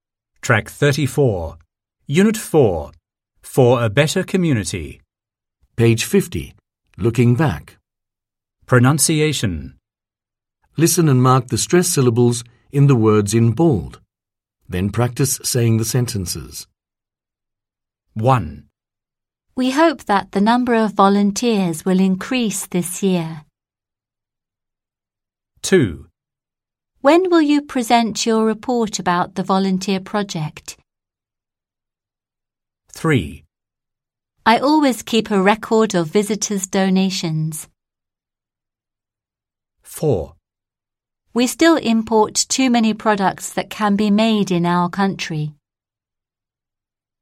1. Pronunciation
Ví dụ: re‘cord, pre‘sent, im‘port